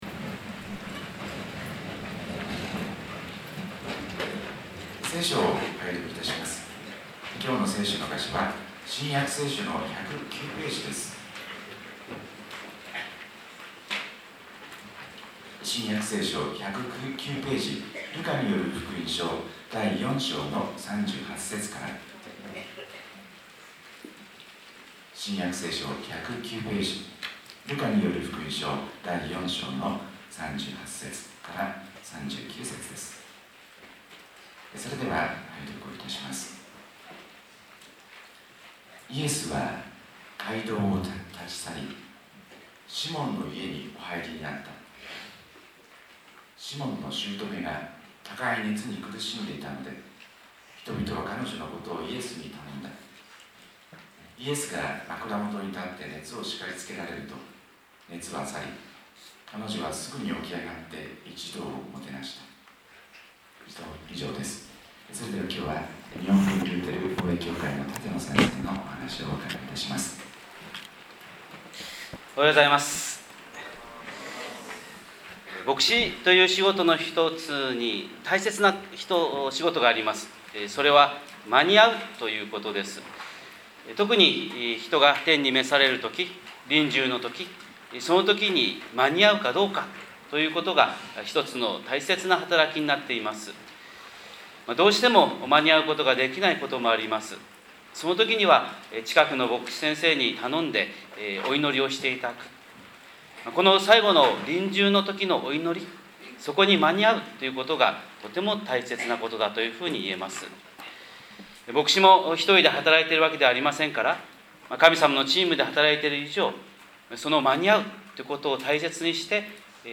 神様の色鉛筆（音声説教）